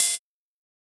UHH_ElectroHatB_Hit-35.wav